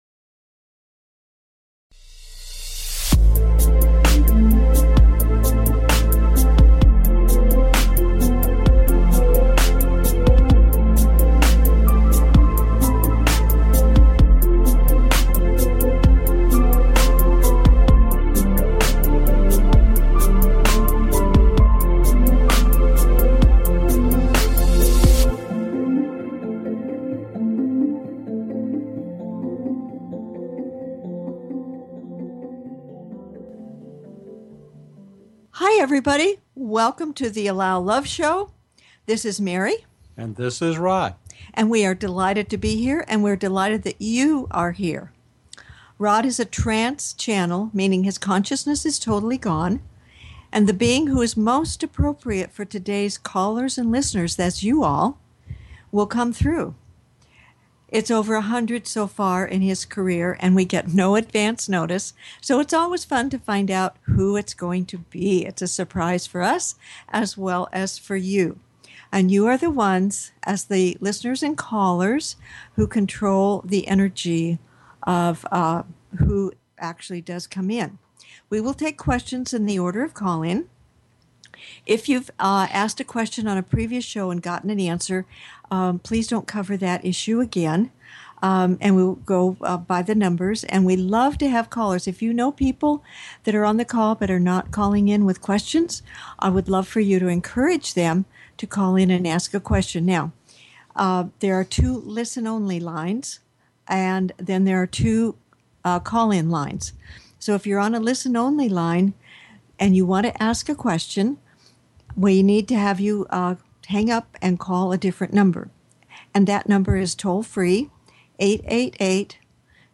Talk Show Episode, Audio Podcast, The Allow Love Show and with Archangel Gabriel on , show guests , about Archangel Gabriel,Angel Gabriel, categorized as Paranormal,Ghosts,Philosophy,Spiritual,Access Consciousness,Medium & Channeling
Their purpose is to provide answers to callers’ questions and to facilitate advice as callers request.